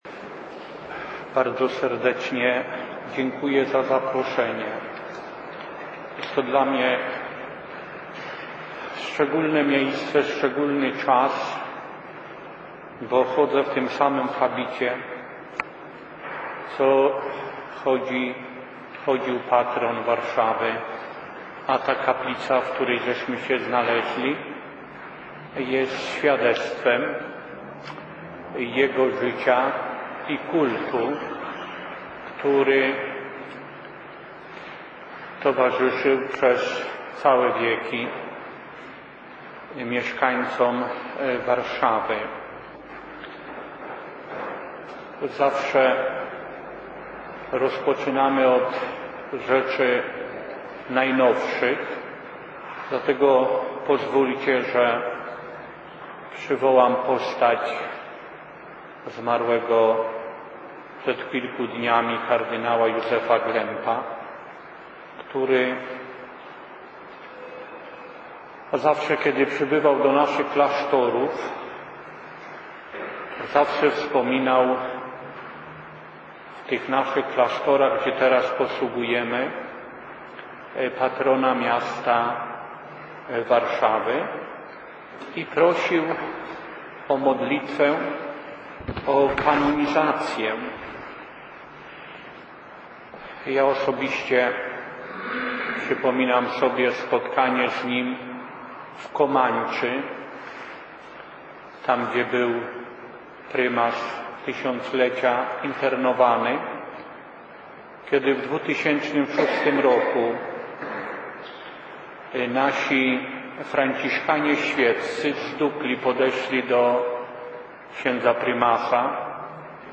NAGRANIE PRELEKCJI
Prelekcja poprzedzona Mszą św. o godz. 15.00 odbyła się w sobotę 9 lutego w kaplicy bł. Władysława z Gielniowa znajdującej się obok głównego ołtarza w kościele św. Anny przy Krakowskim Przedmieściu, ostatnim miejscu posługi bł. Władysława z Gielniowa.